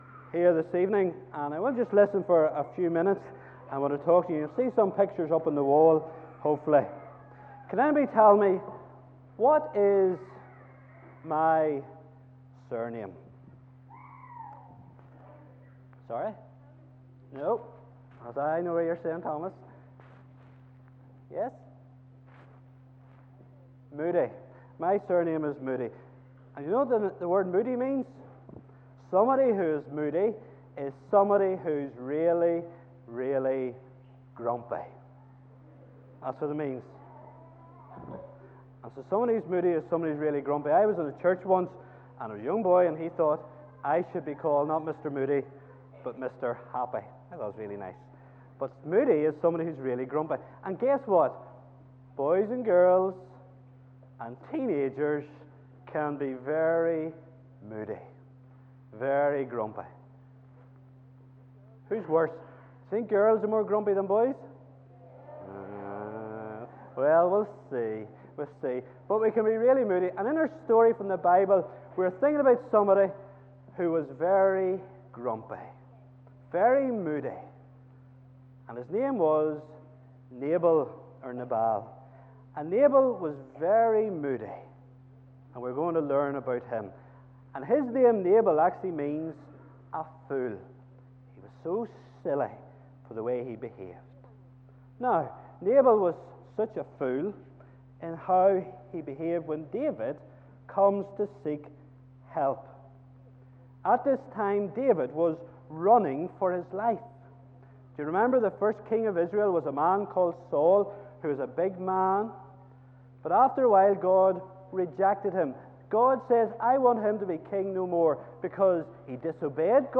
A short message to the boys and girls during a joint Boys' Brigade and Girls' Brigade enrolment service.